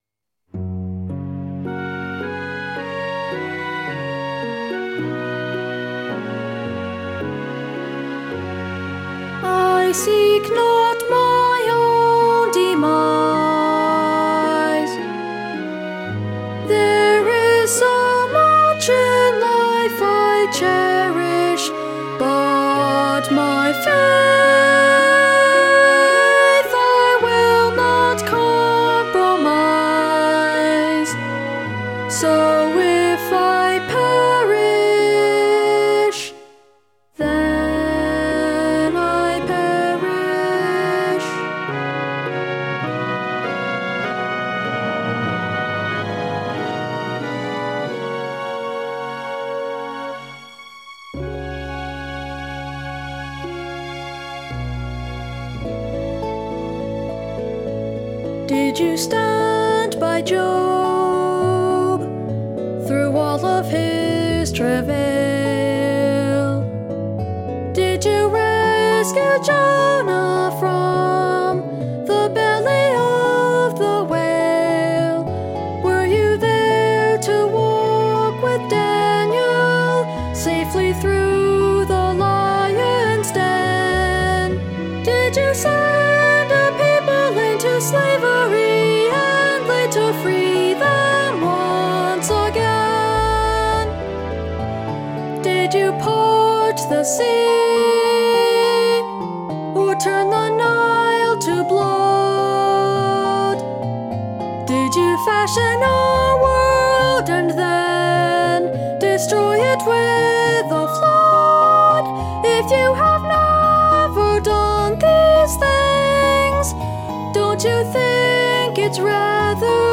The world premier production of this original opera by NCFO founder David Bass was performed at the King Open School, 850 Cambridge St, Cambridge, MA in late March, 2004.